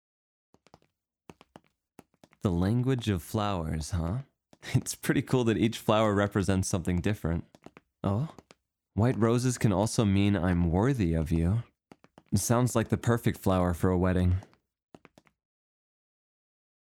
推特ボイス試聽